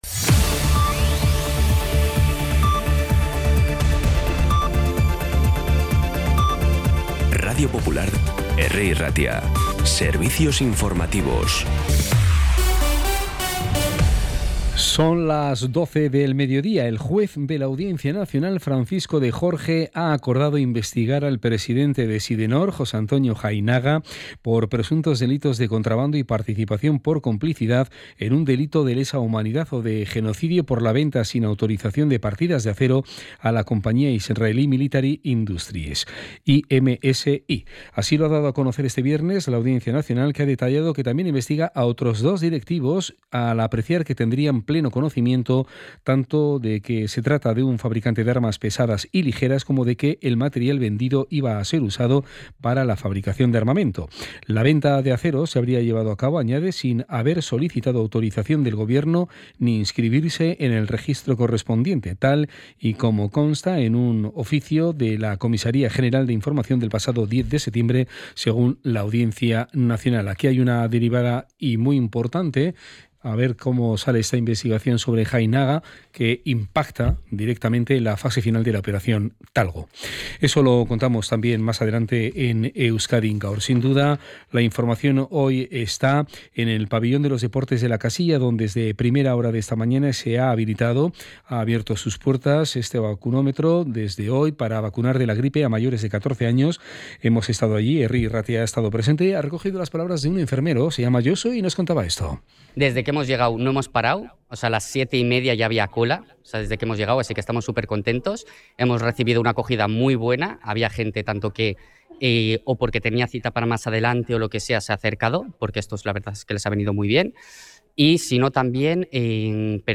Las noticias de Bilbao y Bizkaia del 24 de octubre a las 12
La última hora más cercana, de proximidad, con los boletines informativos de Radio Popular.
Los titulares actualizados con las voces del día. Bilbao, Bizkaia, comarcas, política, sociedad, cultura, sucesos, información de servicio público.